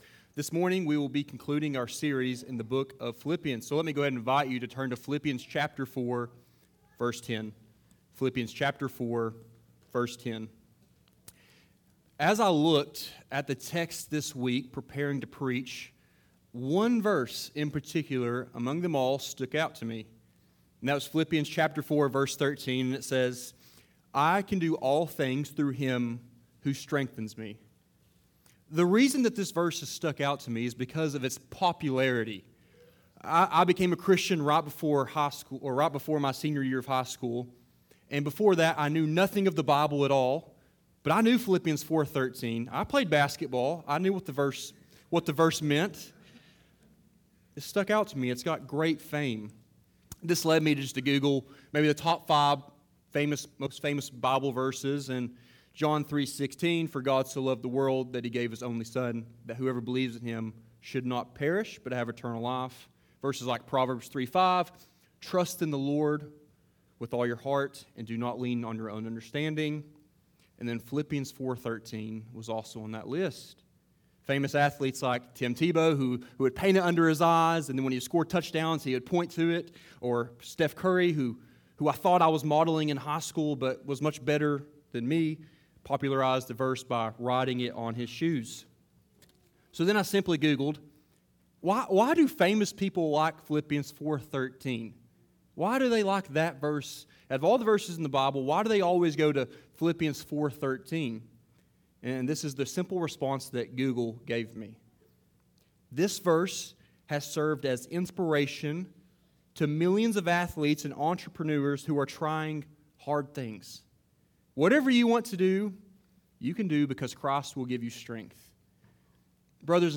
Sermons | Rowan Community Church